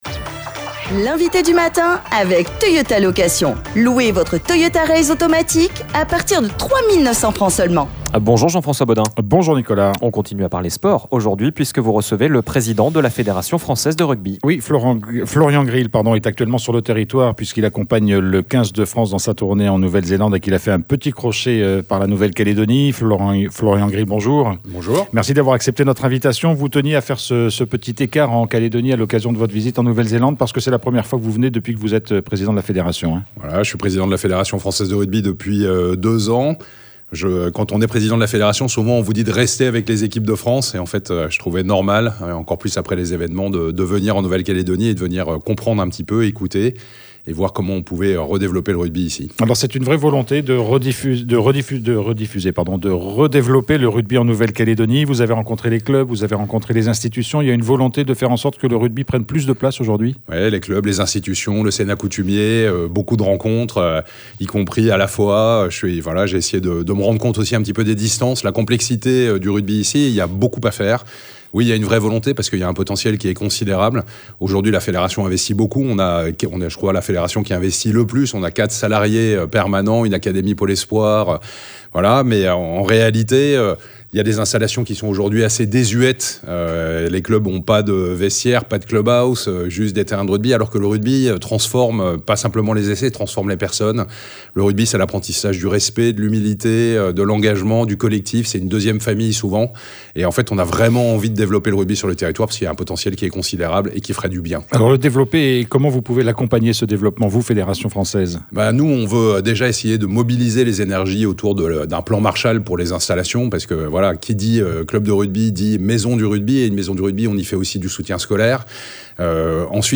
Nous sommes revenus sur cette visite avec le président de la FFR, Florian Grill.